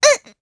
Lilia-Vox_Damage_jp_01.wav